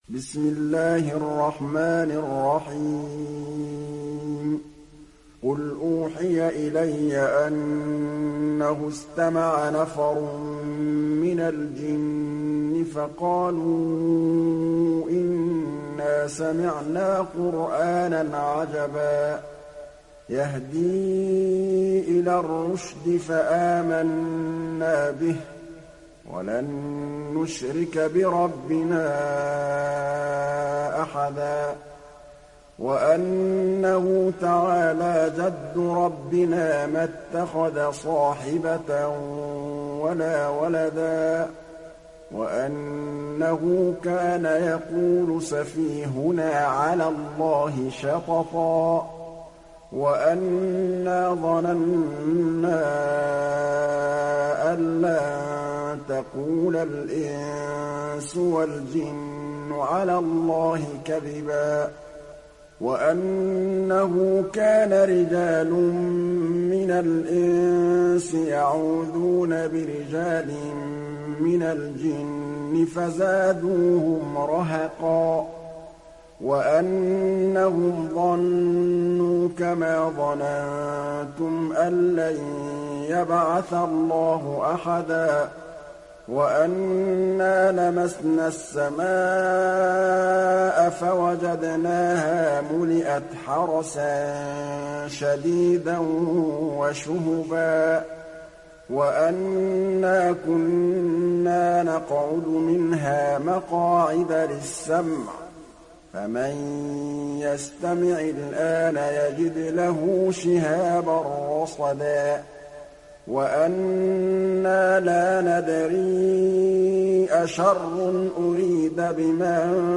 دانلود سوره الجن mp3 محمد محمود الطبلاوي روایت حفص از عاصم, قرآن را دانلود کنید و گوش کن mp3 ، لینک مستقیم کامل